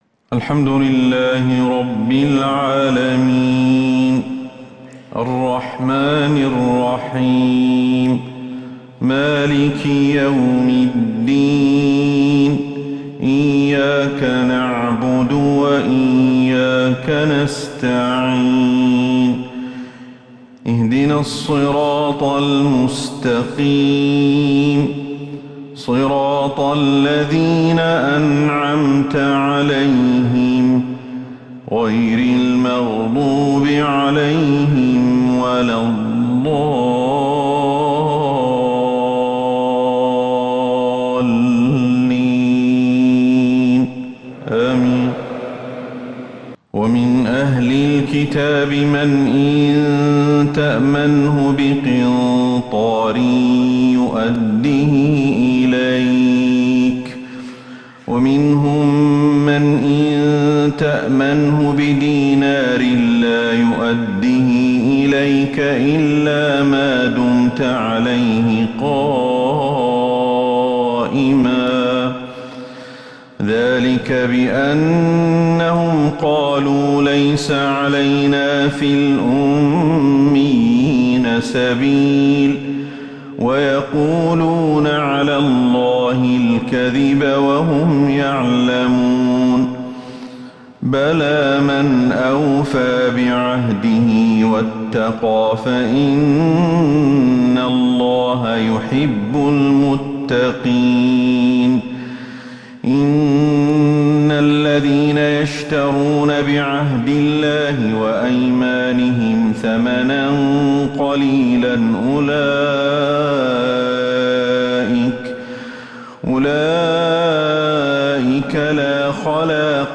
فجر الاثنين 18 جمادى الاولى 1444هـ ما تيسر من سورة {آل عمران} > 1444هـ > الفروض - تلاوات